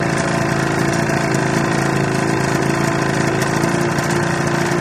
20 hp Johnson Boat Idle Loop